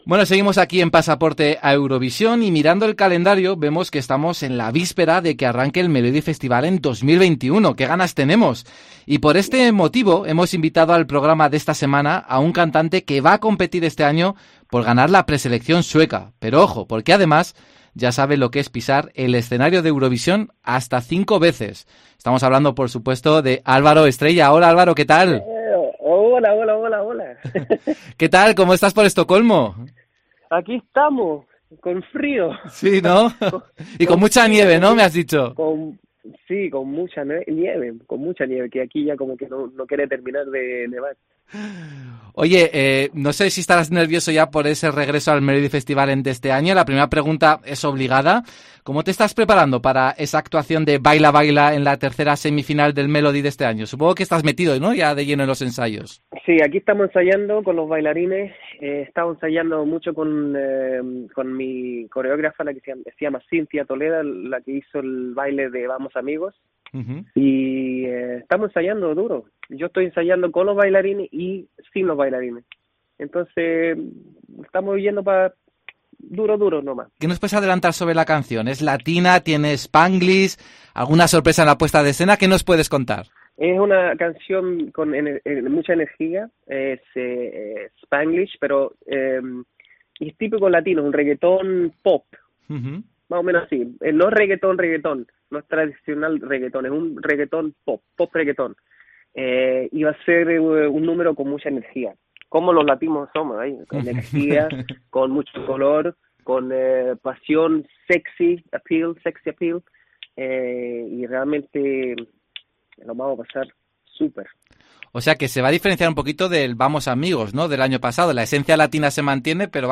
En una entrevista para "Pasaporte a Eurovisión", Álvaro Estrella desvela que su candidatura de este año es un 'reguetón pop'.